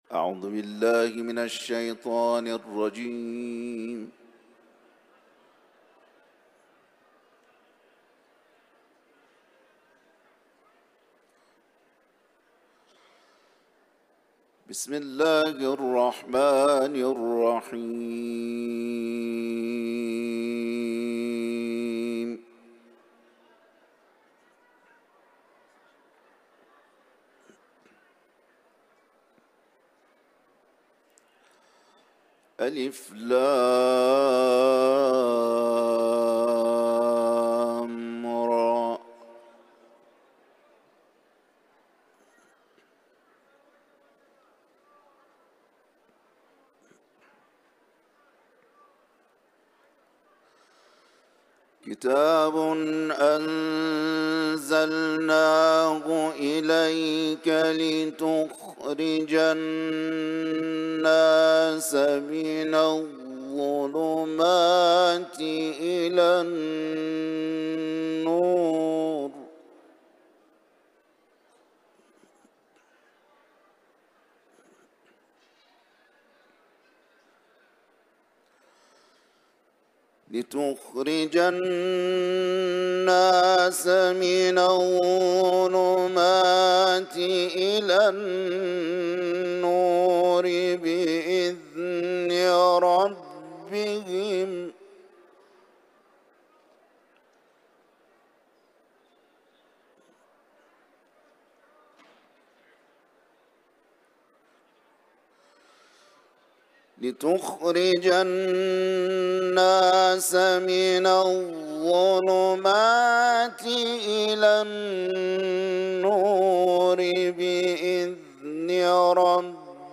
Kuran tilaveti ، İbrahim Suresi